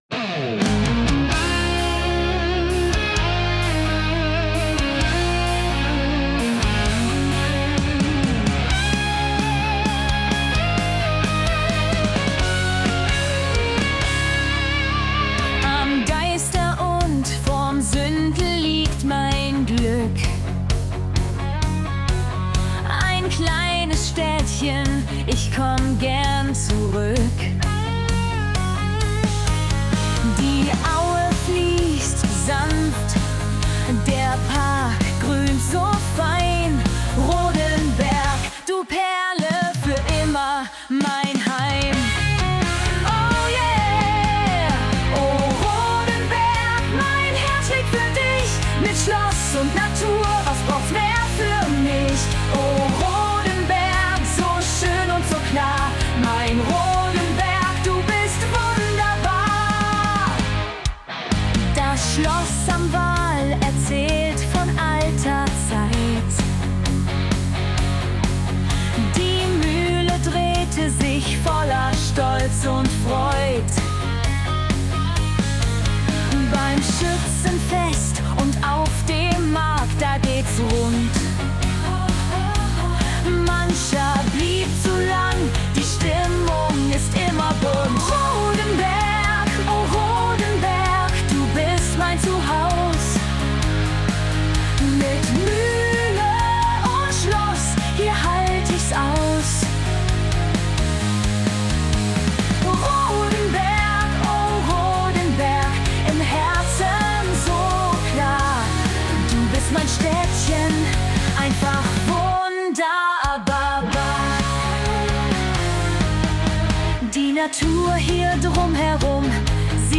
Song für unsere Heimatstadt